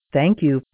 Asterisk sounds
Adding .wav files for the payphone sounds, since these were the ones that were originally generated using the audio editor. 2015-06-01 22:33:52 -07:00 12 KiB Raw History